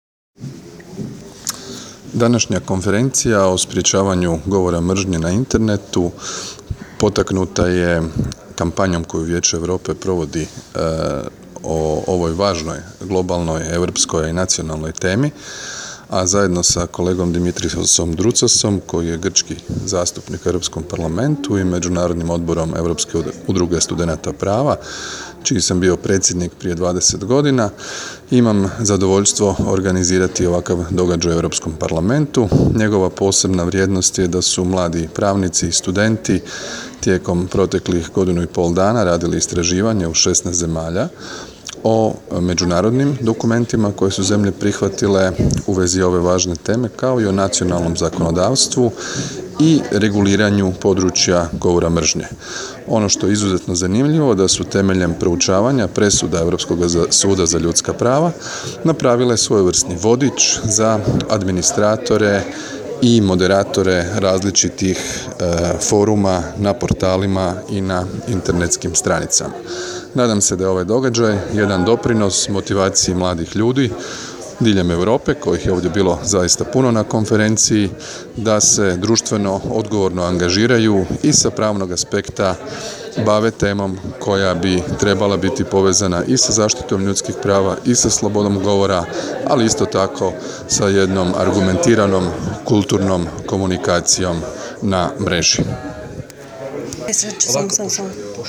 Zvučna snimka izjave Andreja Plenkovića, zastupnika u Europskom parlamentu